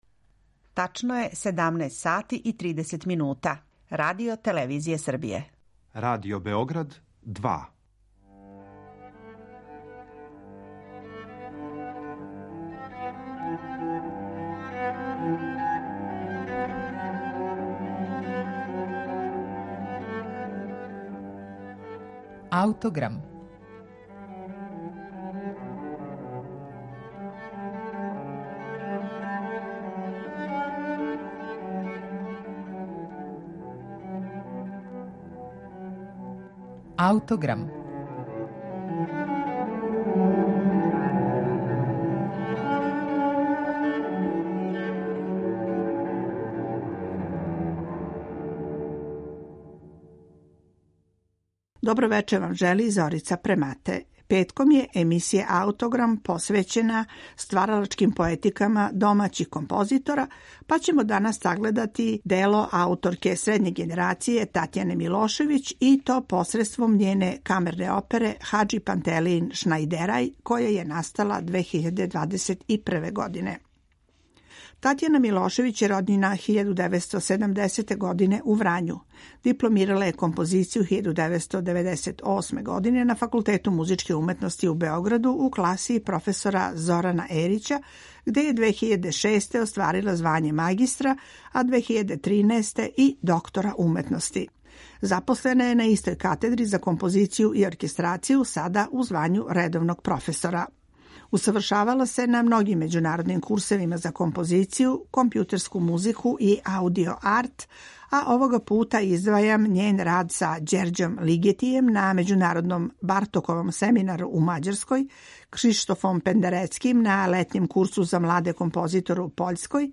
Камерна опера